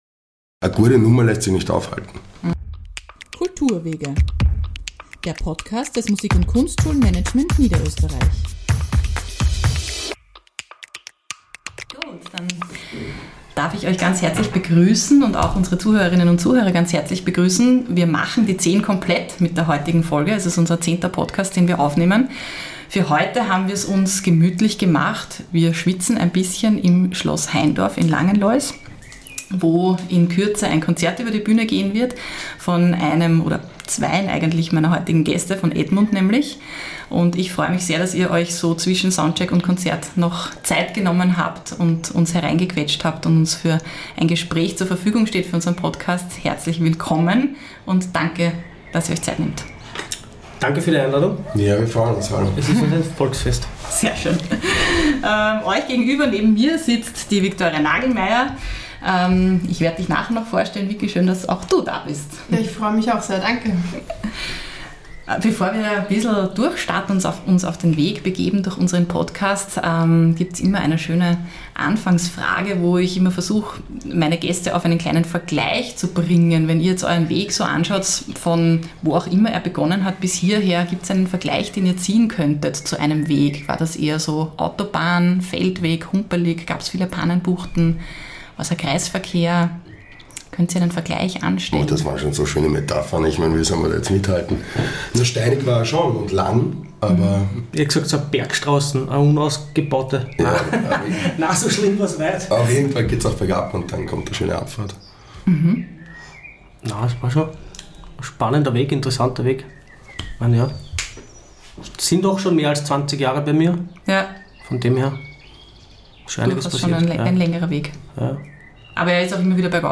Interview. Sie besprechen Erfolgswege und Lebensziele.